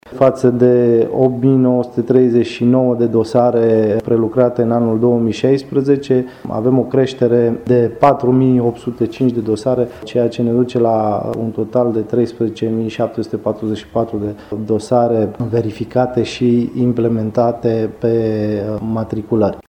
Prefectul judeţului Covasna, Sebastian Cucu: